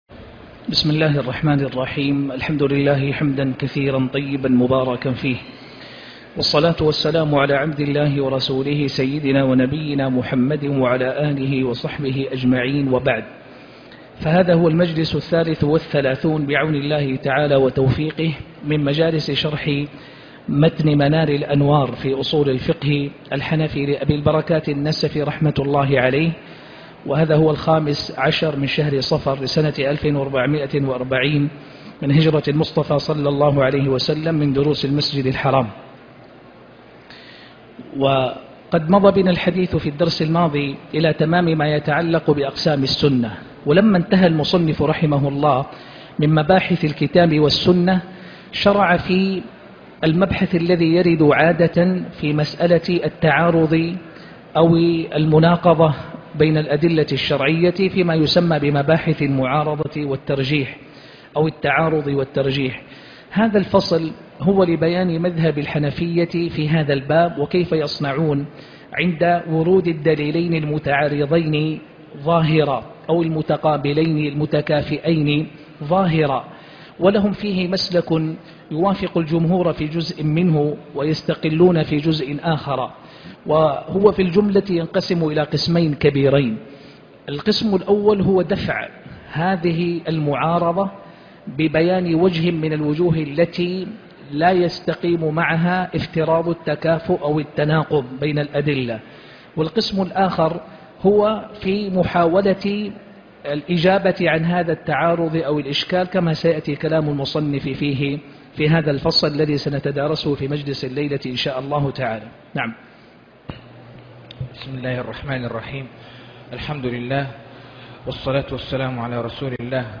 شرح منار الانوار الدرس 33